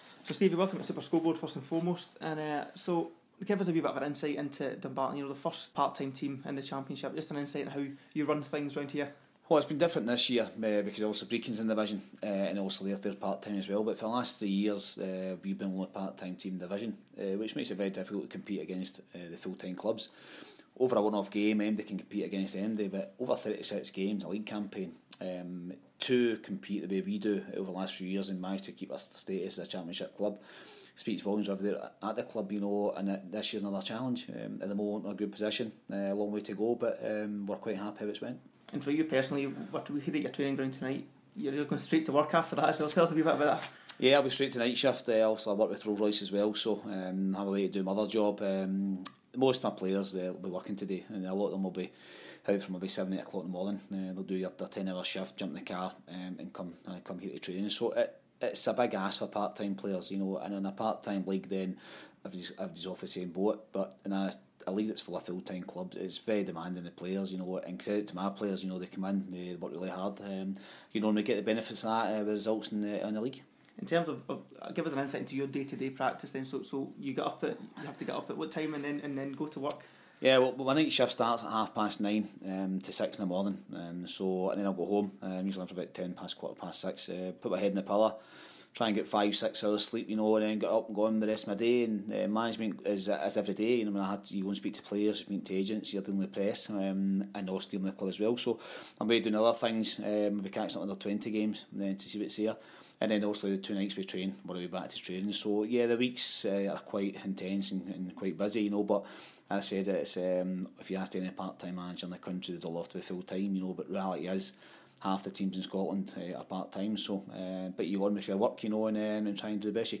speaks to our sports reporter